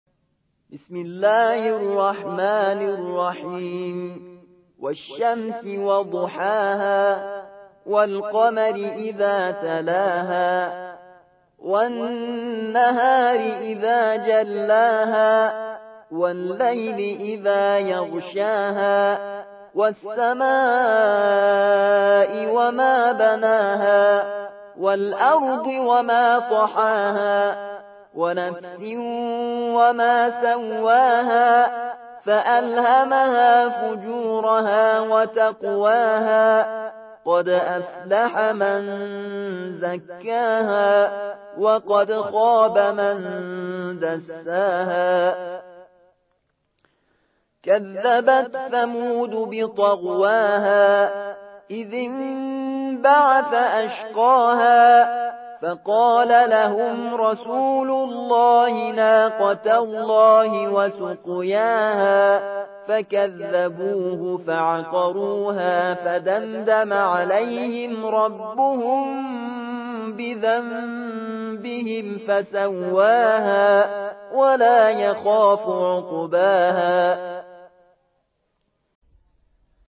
91. سورة الشمس / القارئ